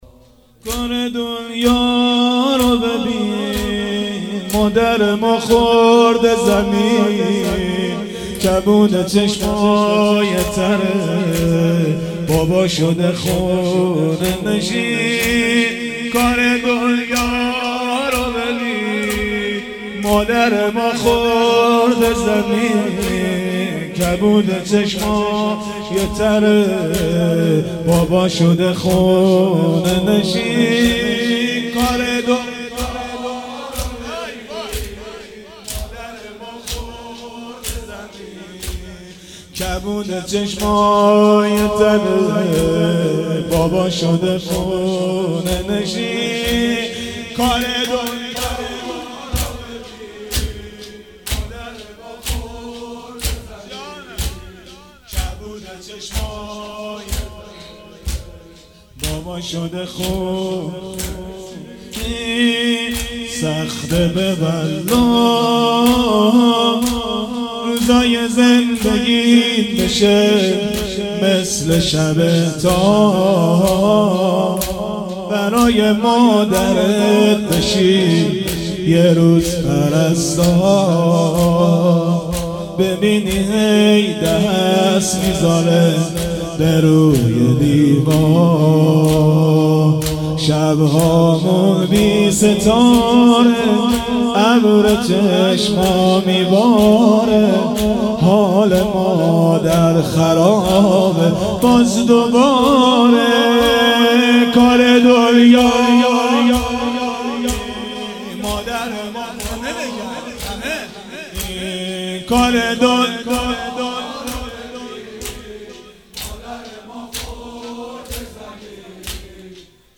جلسه هفتگی 22-11-93حسینیه ی سپهسالار کربلا
روضه حضرت زهرا (س)
سینه زنی
مدح امیرالمومنین